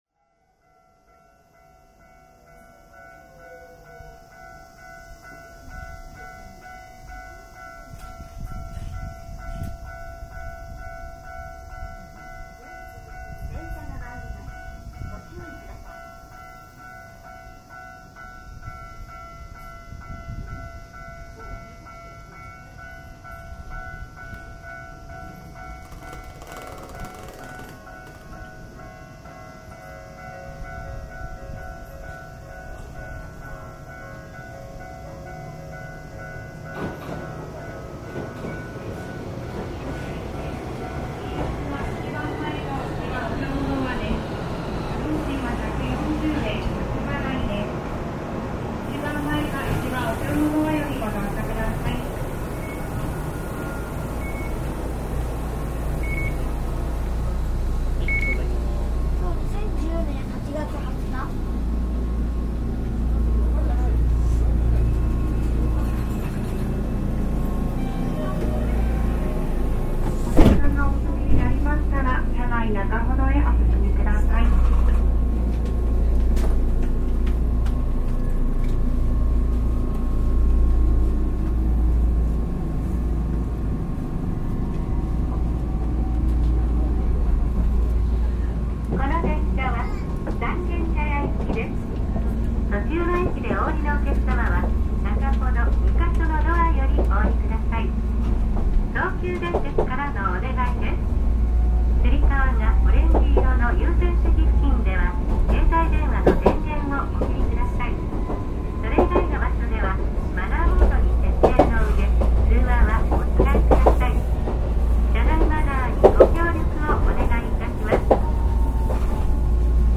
山下～松陰神社前間　車内